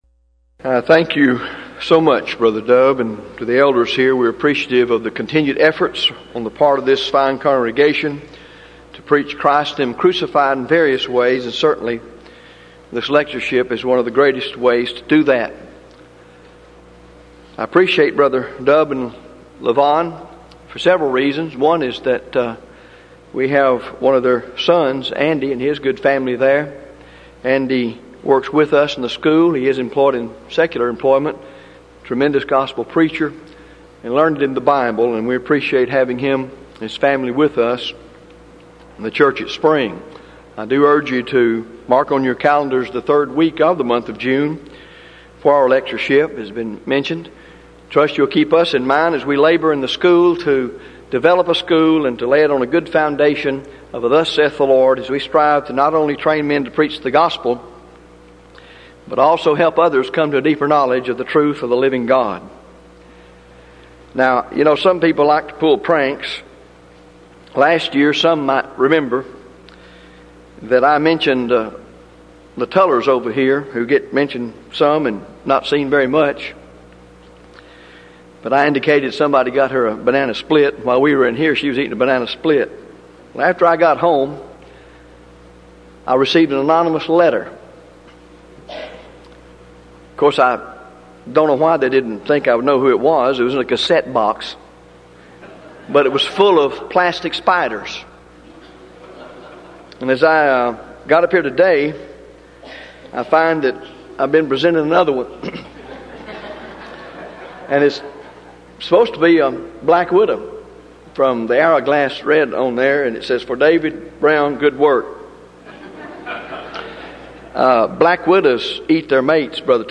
Event: 1994 Denton Lectures Theme/Title: Studies In Joshua, Judges And Ruth